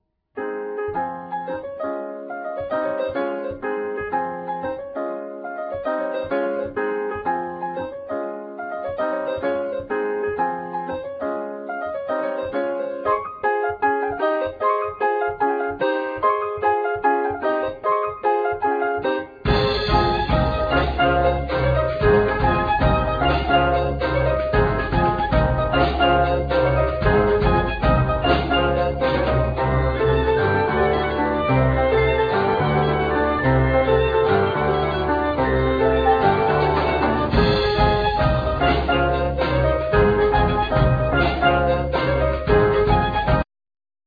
Violin
Basson
Piano
Doublebass
Drums,Percussions
Vibes,Marimba,Percussions
Vocals
Trumpet,Flugelhorn
Trombone
Bass Clarinat